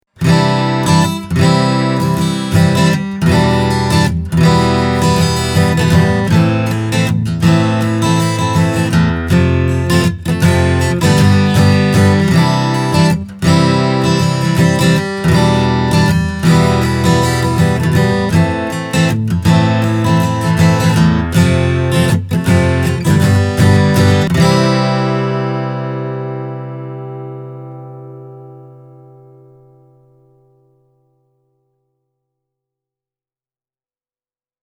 TW1000HSRE:n soundissa on iso ja lämmin basso, hyvin moniulotteinen keskialue, sekä kimaltelevaa diskanttia.
C3000 – plektralla